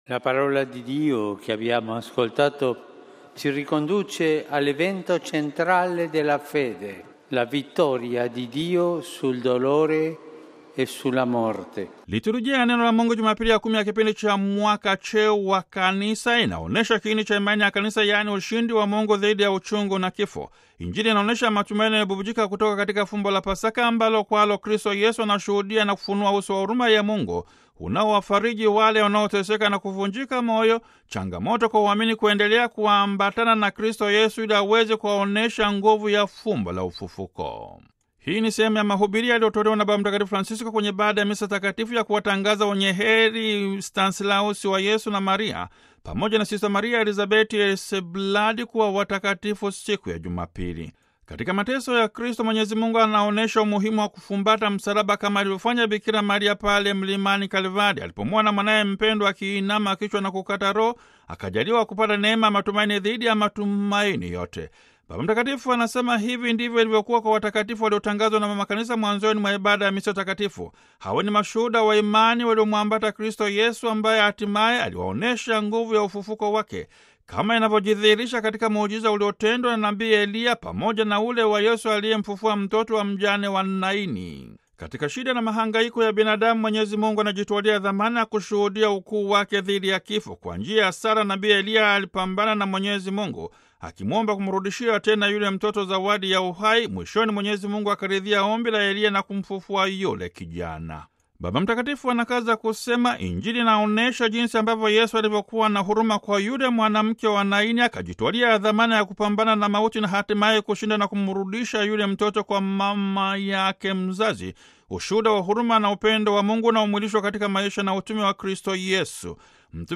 Hii ni sehemu ya mahubiri yaliyotolewa na Baba Mtakatifu Francisko kwenye Ibada ya Misa Takatifu ya kuwatangaza Wenyeheri Stanislaus wa Yesu na Maria pamoja na Sr. Maria Elizabeth Hesselblad kuwa watakatifu, Jumapili tarehe 5 Juni 2016 kwenye Uwanja wa Kanisa kuu la Mtakatifu Petro mjini Vatican.